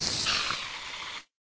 minecraft / sounds / mob / spider / death.ogg